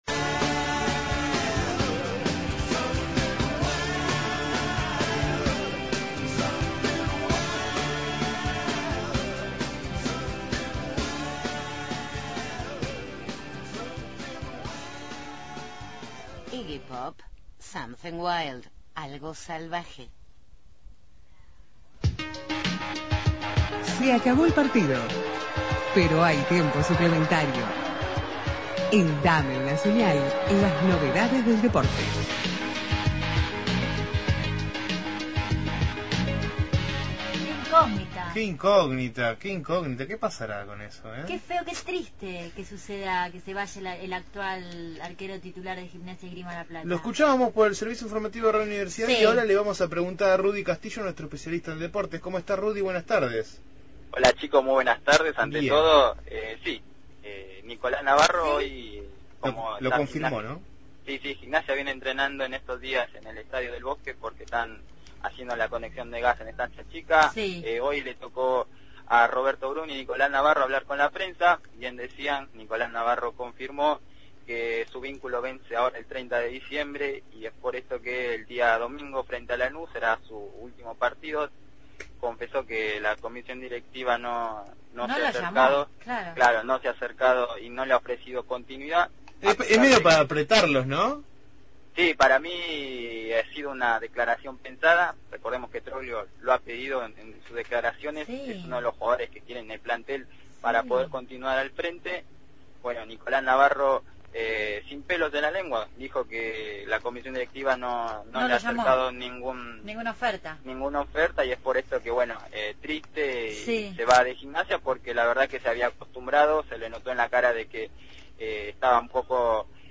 Columna de deportes